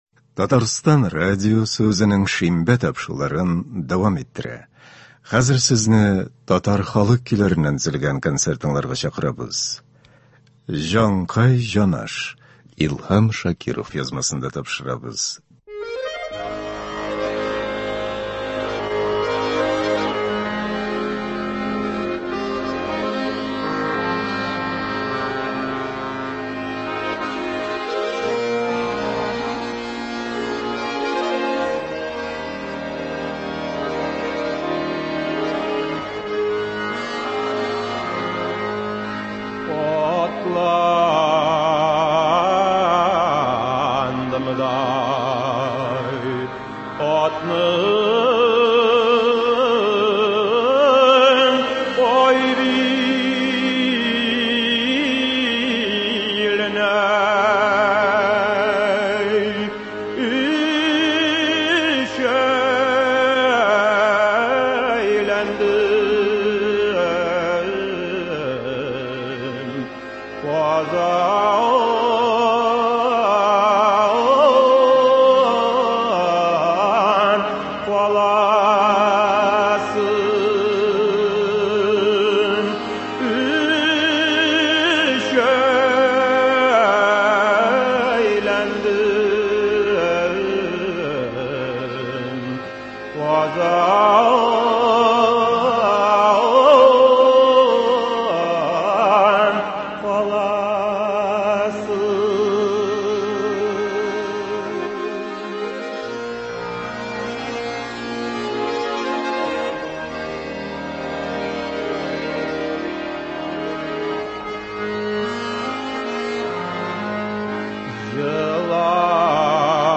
Татар халык көйләре (19.08.23)